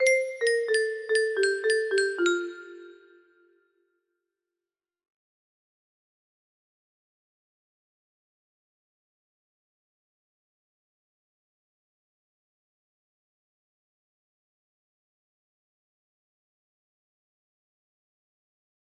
Starty music box melody